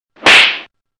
skipping.mp3